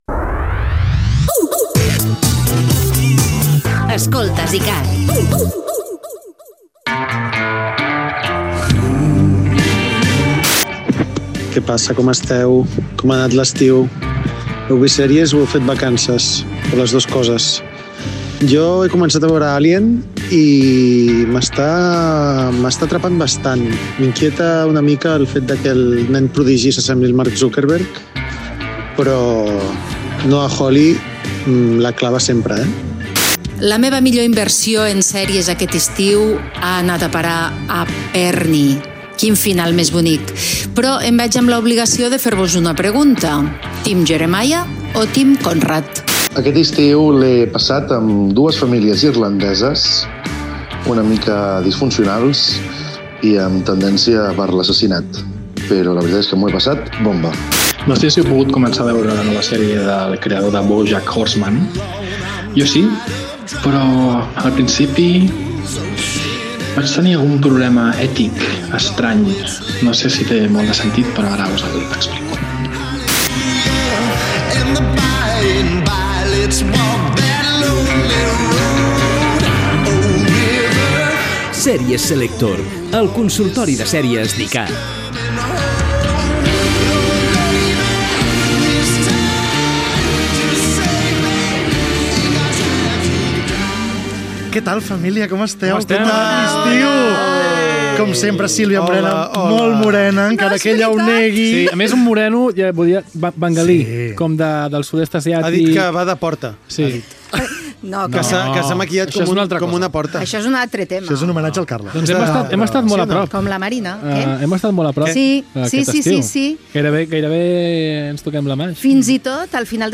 Indicatiu de la ràdio, Les sèries que ha vist l'equip del programa aquest estiu. Indicatiu del programa, diàleg de l'equip sobre els seus viatges d'estiu, les sèries que es poden veure a la Xina i la sèrie "Alien".